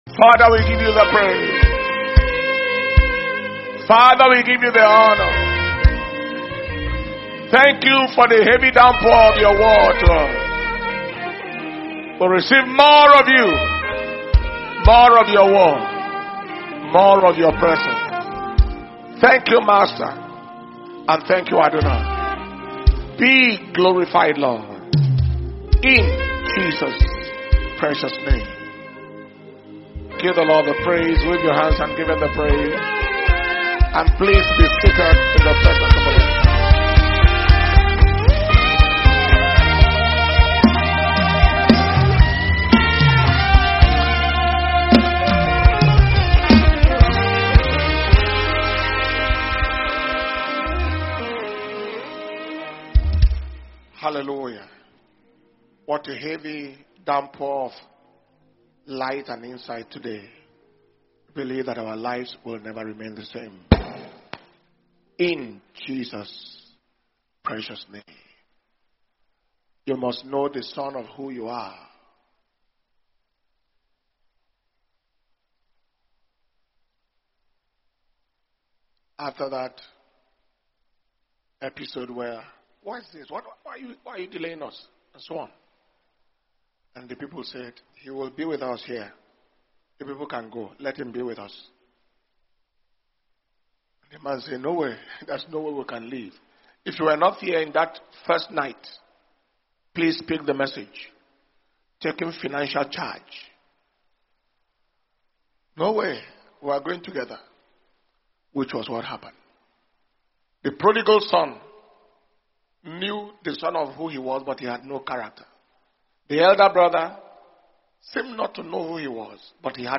May 2025 Destiny Recovery Convention- Day 2 Morning